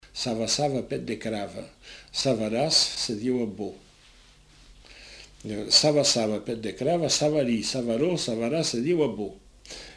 Lieu : Saint-Aventin
Effectif : 1
Type de voix : voix d'homme
Production du son : récité
Classification : formulette